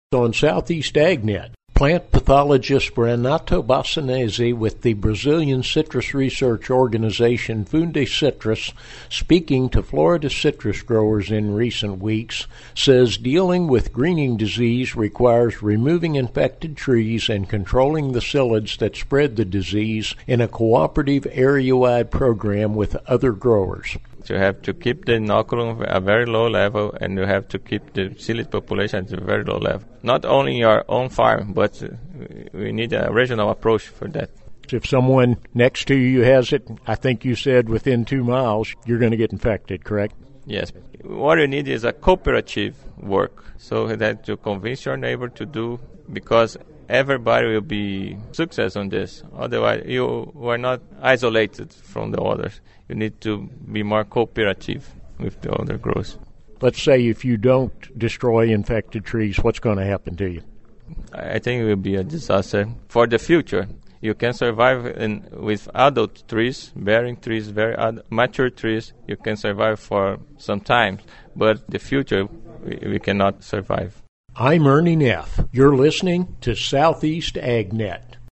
Plant pathologist